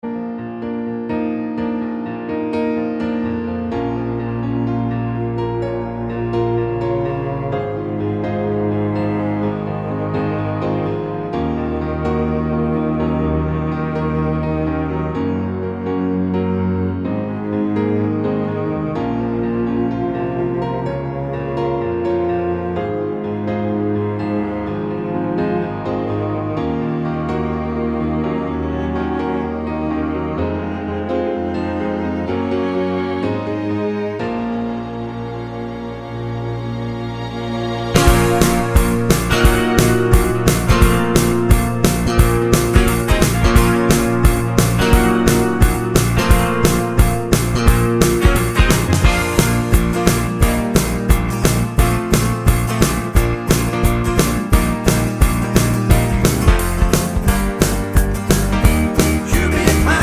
Velocity Mix Pop (1980s) 3:33 Buy £1.50